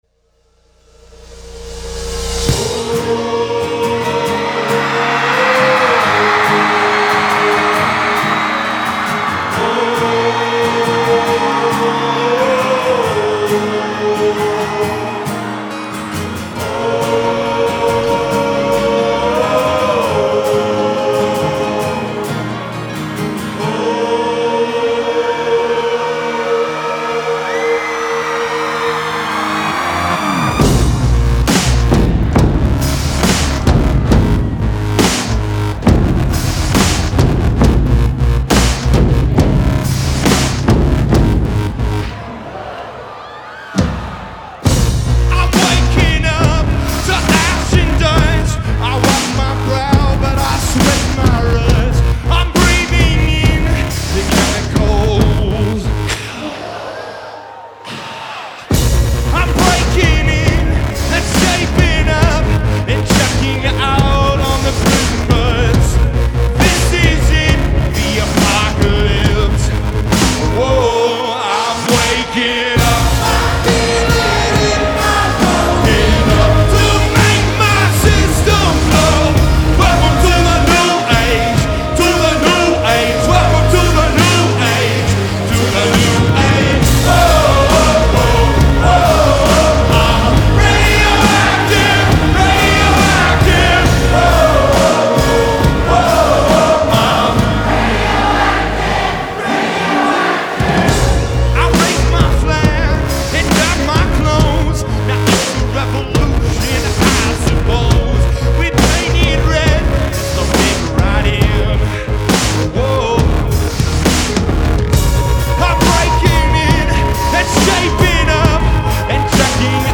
Genre : Alternative & Indie
Live From Red Rocks